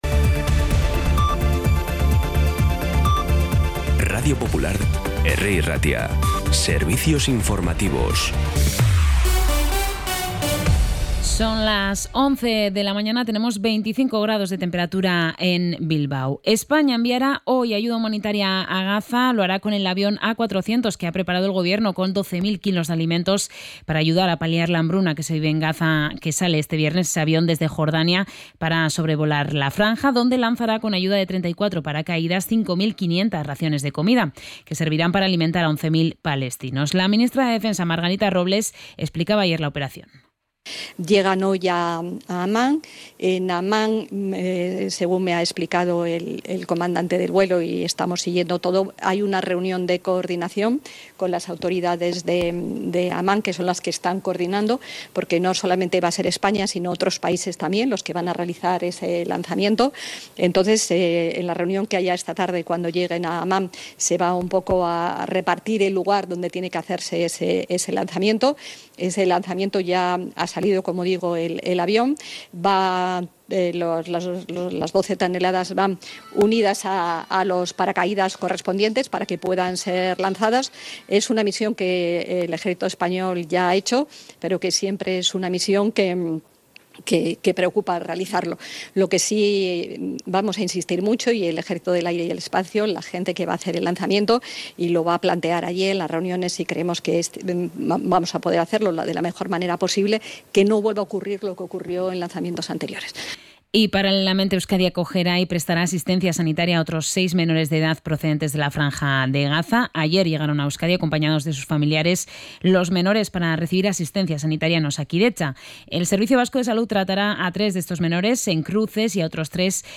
Podcast Bizkaia